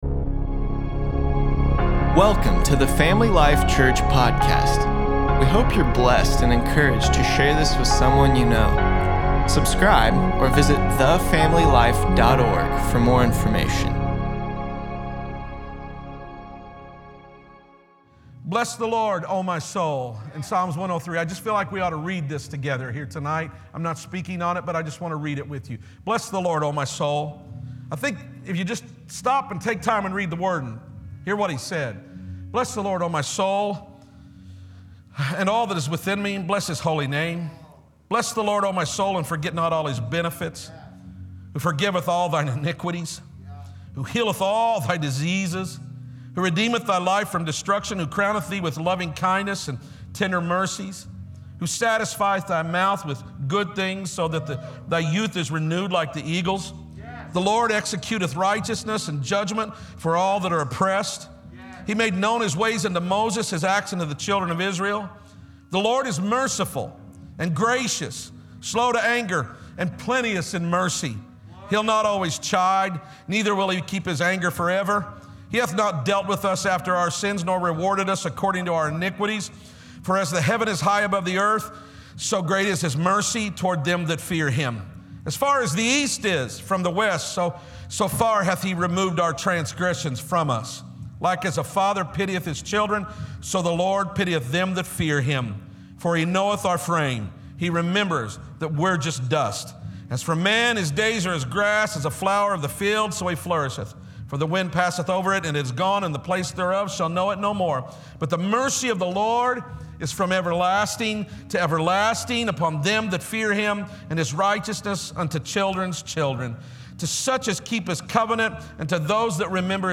2.10.21_sermon.mp3